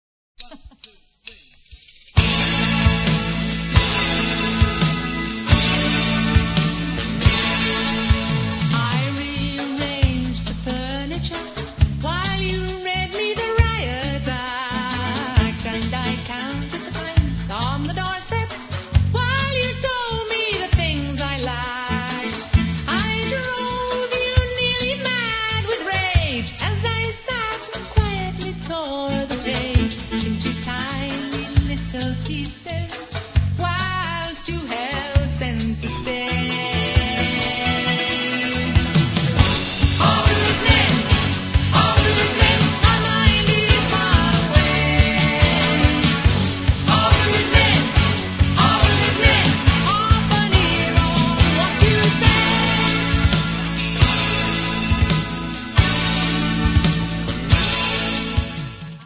First part, 1:18 sec, mono, 22 Khz, file size: 231 Kb.